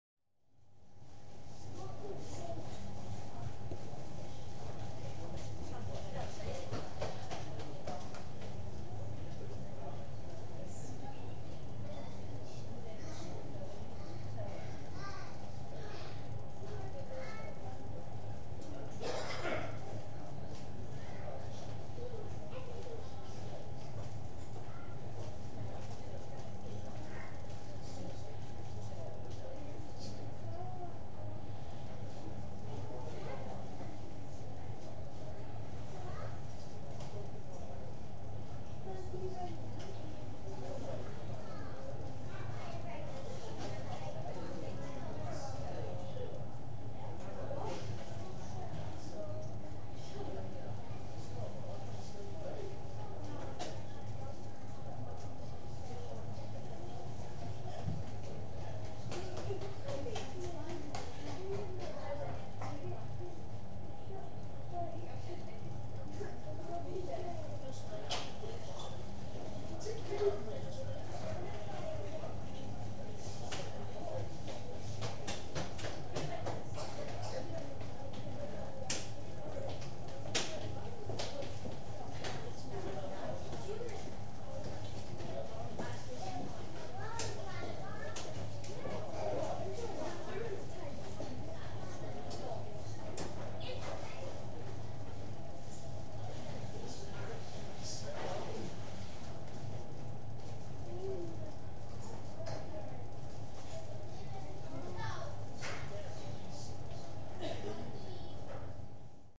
Une petite foule de gens avec des enfants sur un ferry à l’intérieur, embarquement: